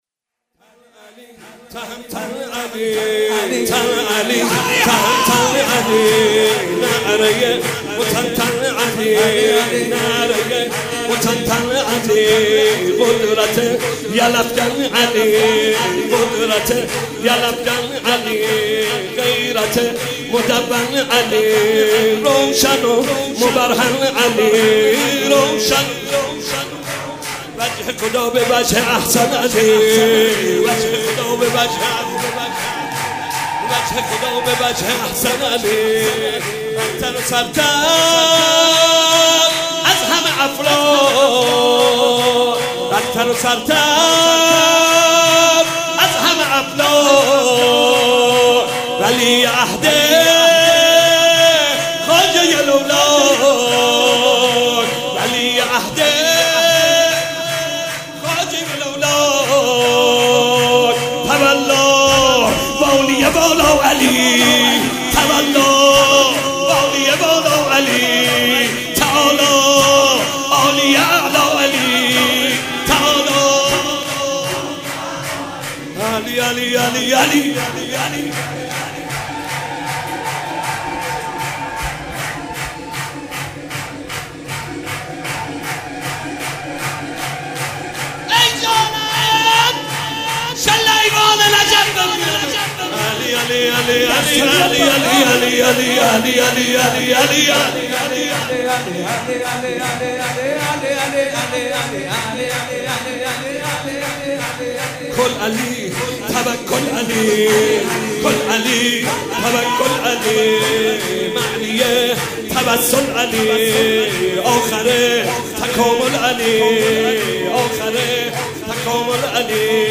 (شور)